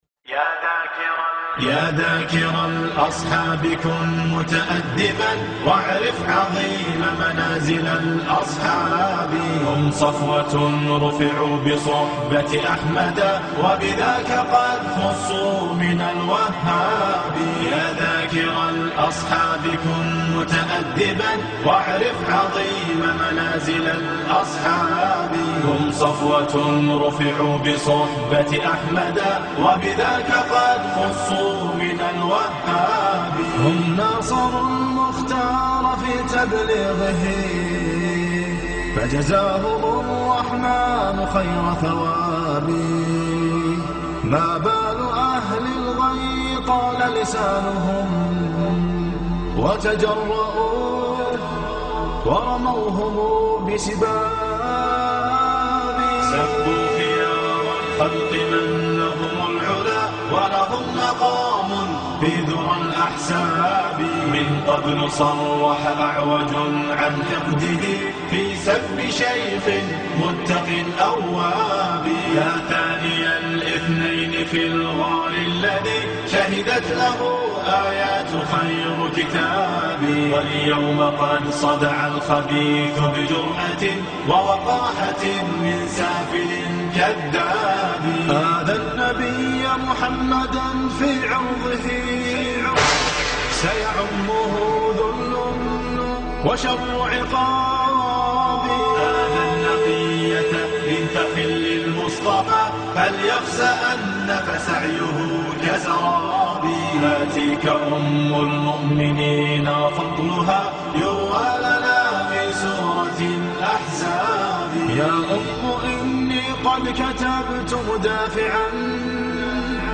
الاناشيد الاسلامية الجديدة MP3